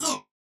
Hurt.wav